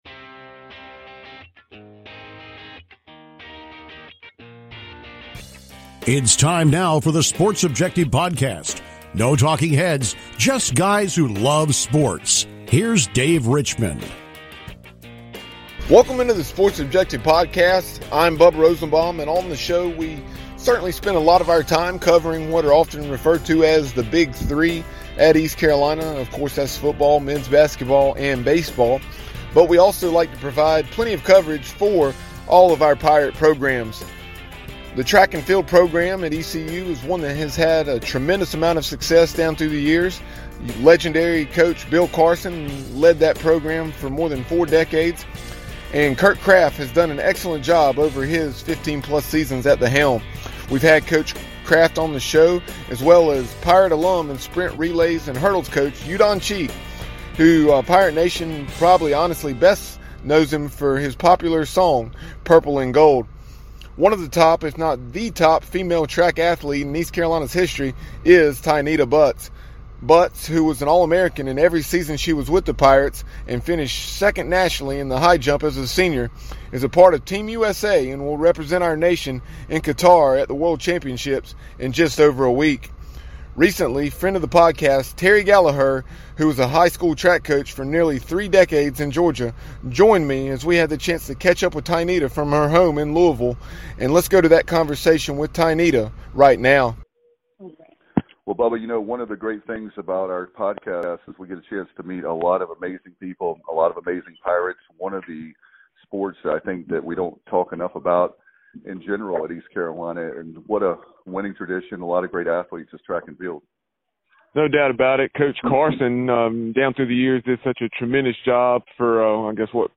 extended conversation